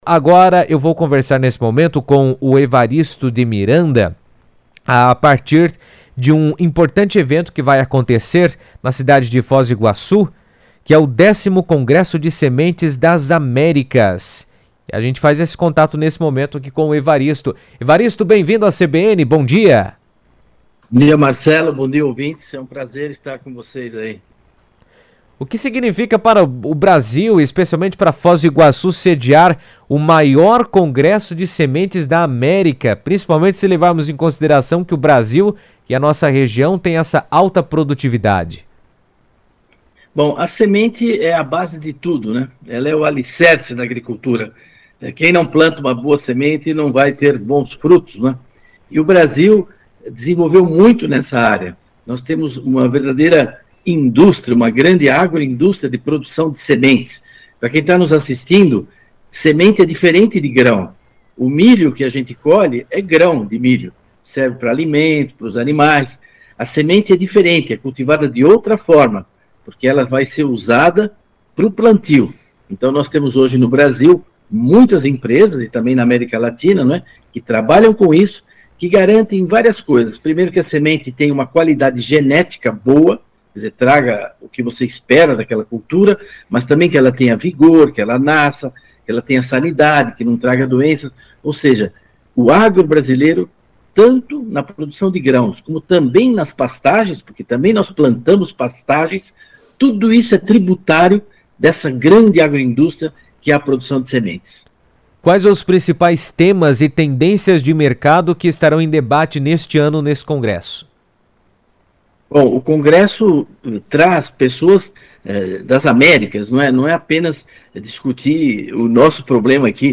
Evaristo de Miranda comentou sobre a importância do congresso em entrevista à CBN, destacando o impacto do encontro para o desenvolvimento da agricultura na região.